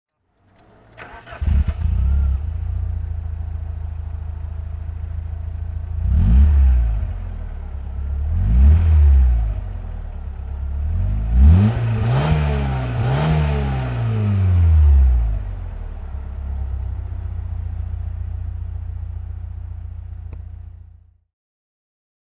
Back in the car, the engine is started conventionally with the ignition key and the melodious-sounding four-cylinder engine in the front starts up. The sporty note is pleasing, the engine is not loud.
Fiat Barchetta (1998) - Starten und Leerlauf